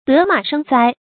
得马生灾 dé mǎ shēng zāi
得马生灾发音
成语注音ㄉㄜ ㄇㄚˇ ㄕㄥ ㄗㄞ